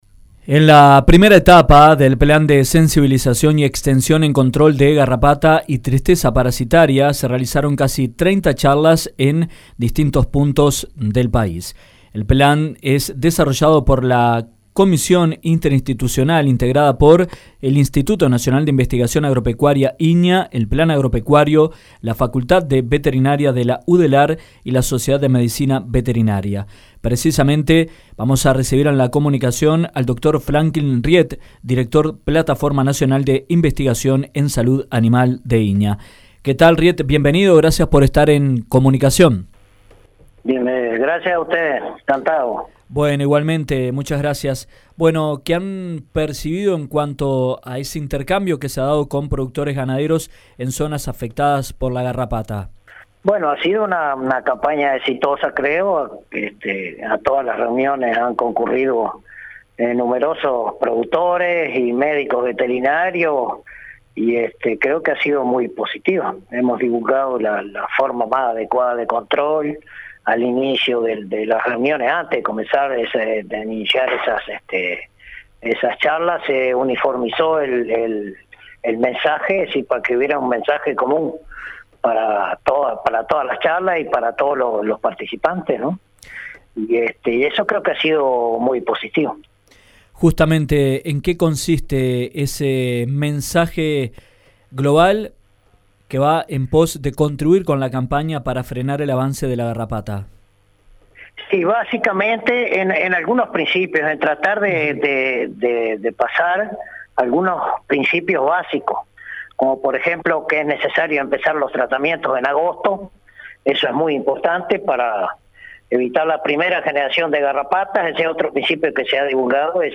En la primera etapa del Plan de Sensibilización y Extensión en control de garrapata y tristeza parasitaria, se realizaron casi 30 charlas en zonas afectadas. En entrevista con Dinámica Rural